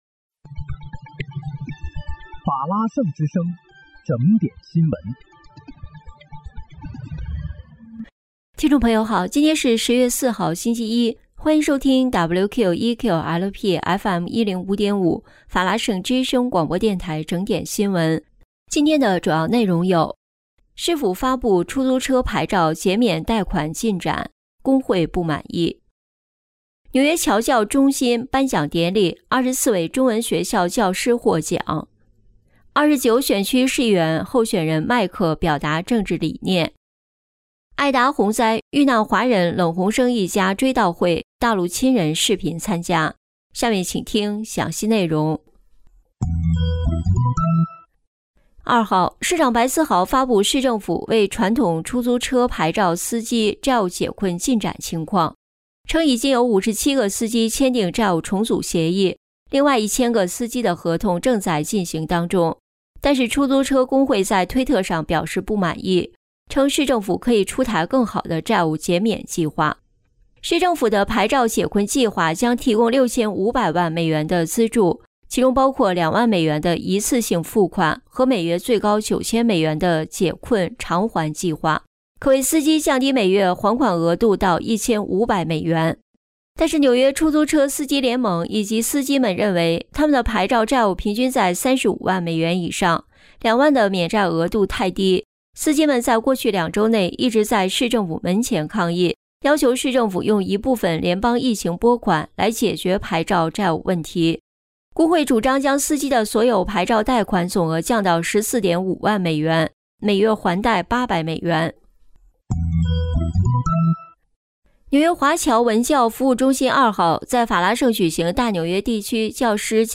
10月4日（星期一）纽约整点新闻